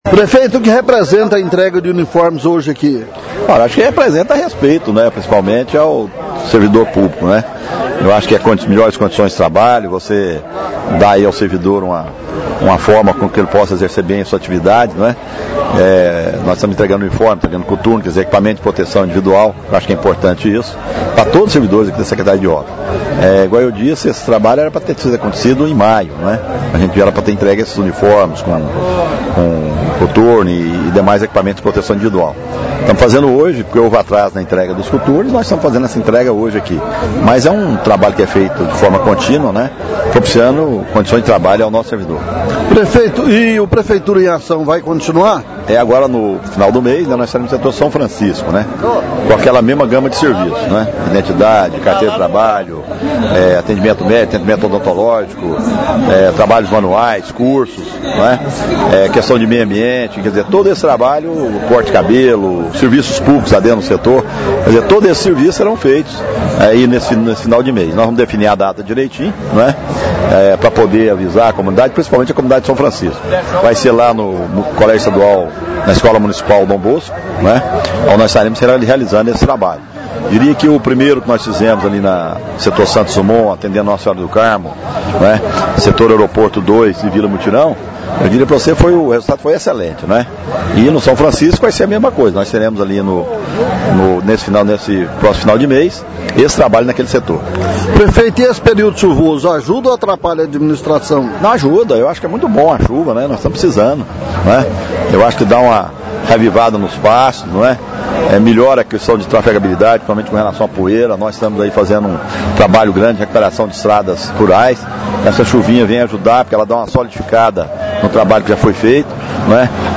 O prefeito fala sobre o assunto com o repórter